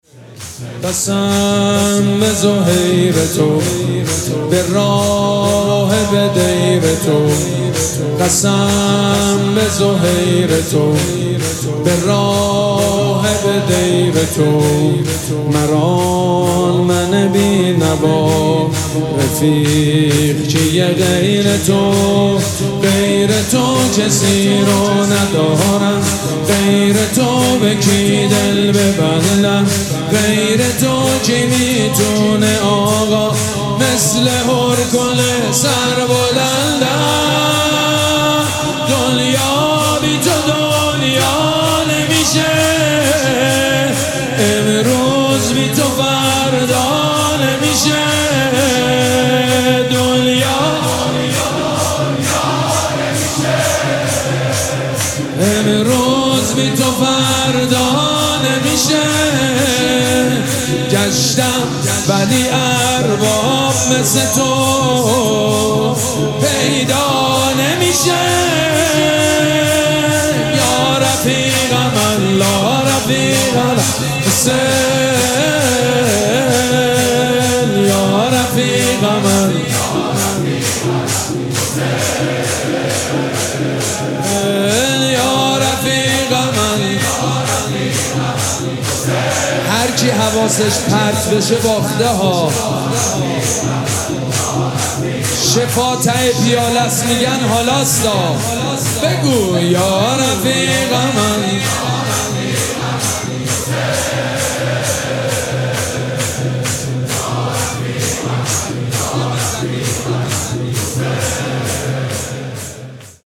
مراسم عزاداری شام غریبان محرم الحرام ۱۴۴۷
شور
مداح
حاج سید مجید بنی فاطمه